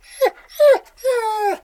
bdog_die_0.ogg